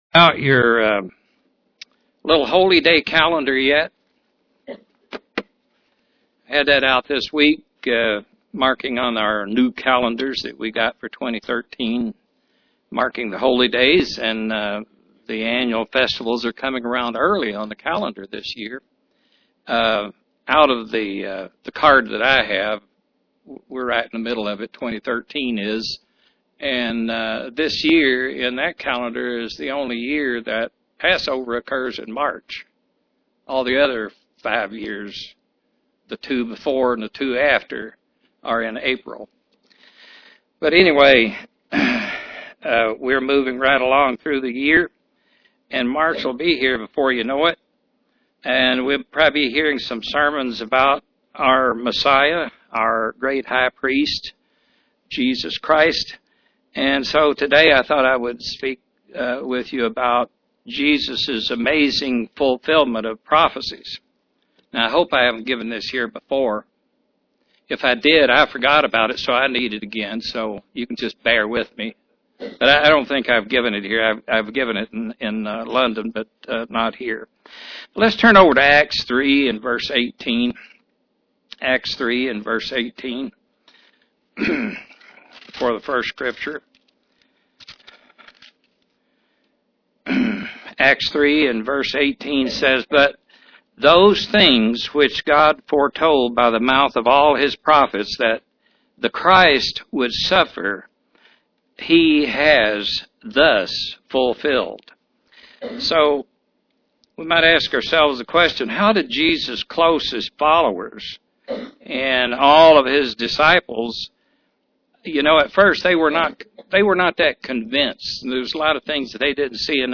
Given in Knoxville, TN
Print Study of the prophecies of the coming of Jesus Christ UCG Sermon Studying the bible?